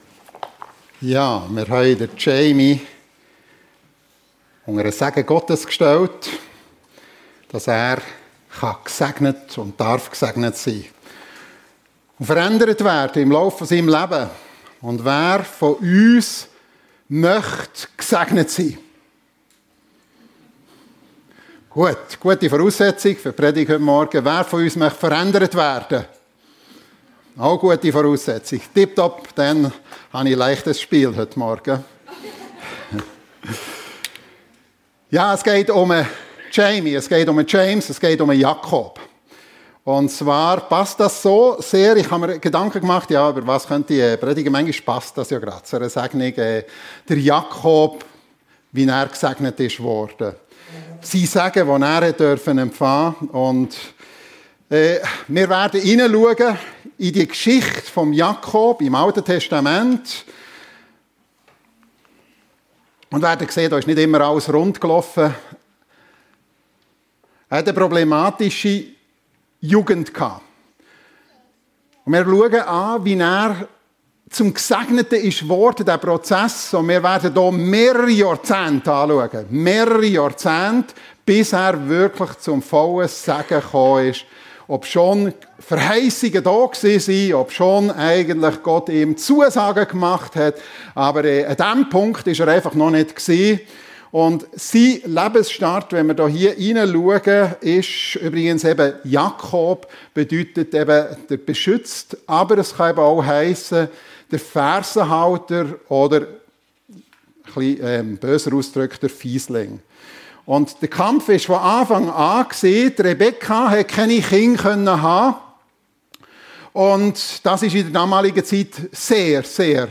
Wie Jakob zum Gesegneten wurde ~ FEG Sumiswald - Predigten Podcast